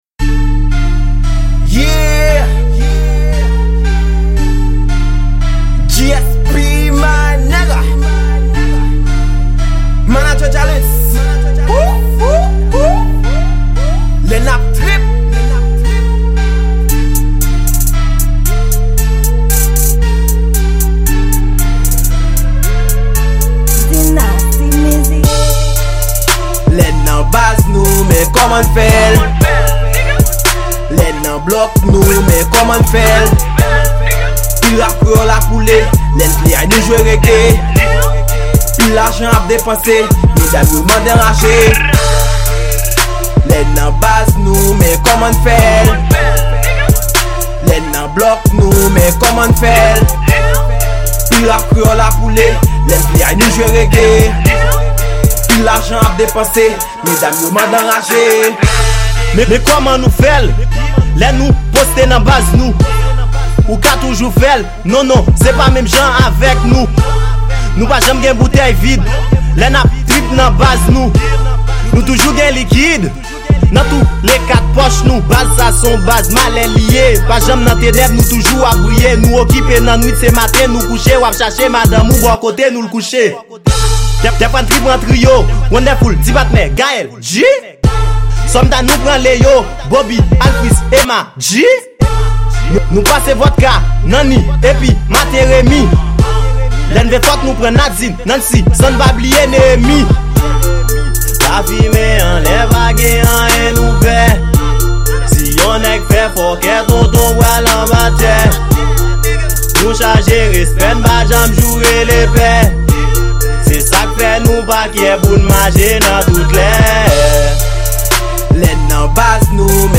Genr: Rap